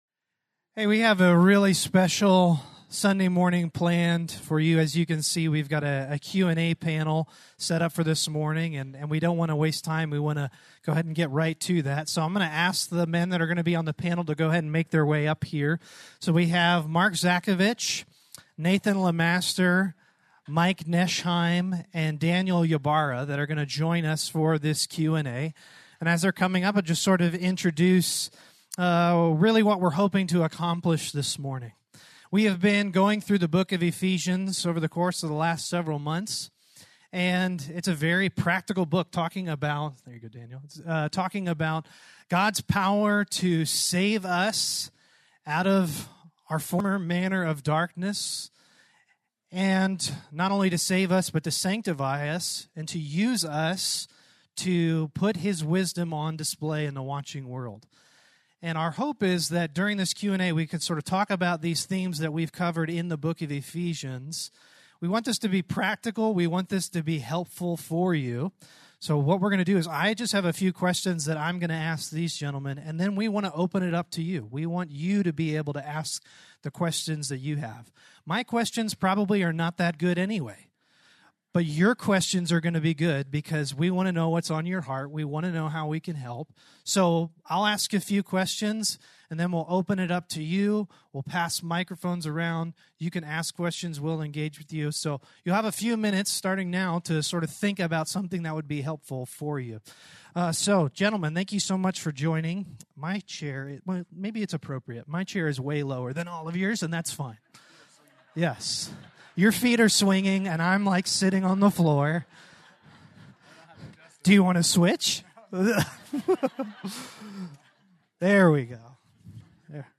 Ephesians Q&A
Please note, due to technical difficulties, this recording skips brief portions of audio.